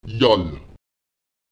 Lautsprecher yal [jal] Frage nach einem Numerale